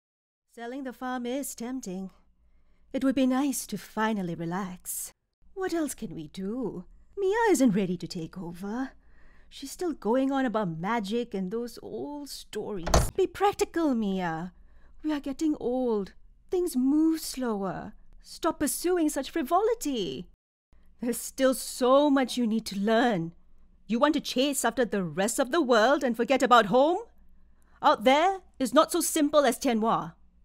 Never any Artificial Voices used, unlike other sites.
Character, Cartoon and Animation Voice Overs
Adult (30-50) | Yng Adult (18-29)